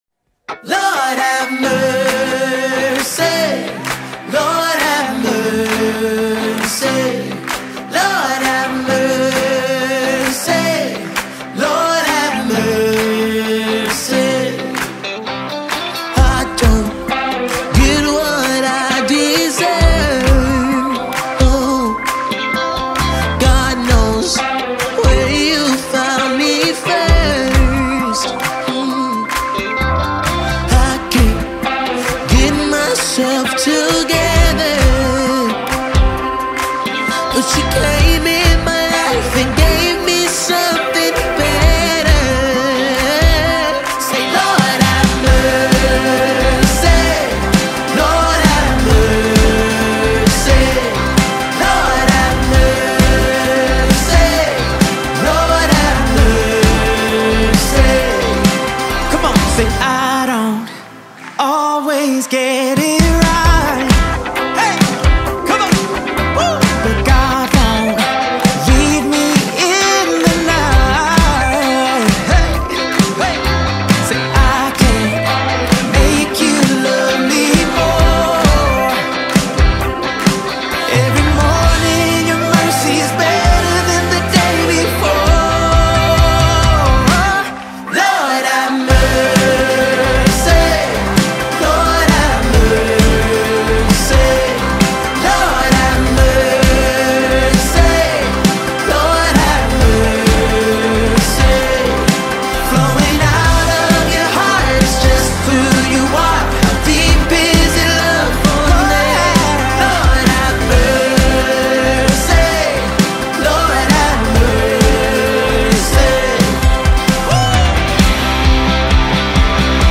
2026 single